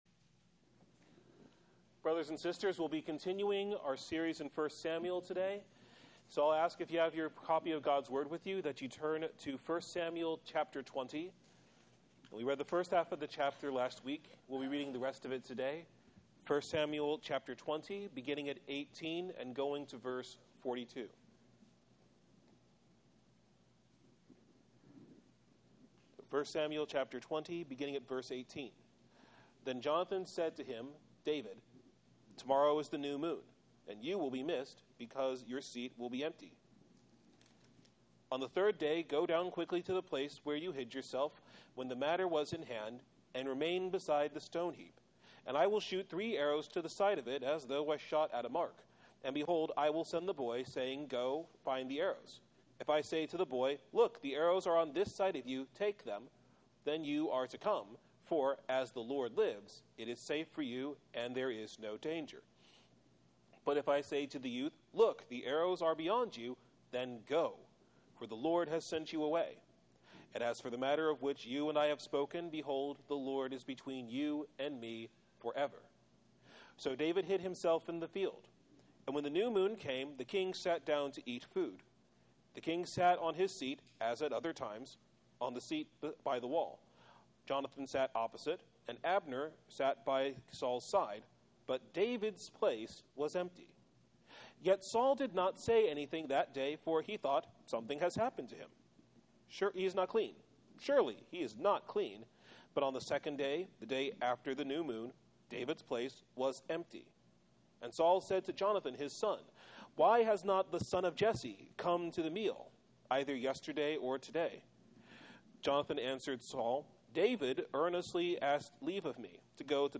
Covenant Friends Forever - Providence Reformed Church of Bakersfield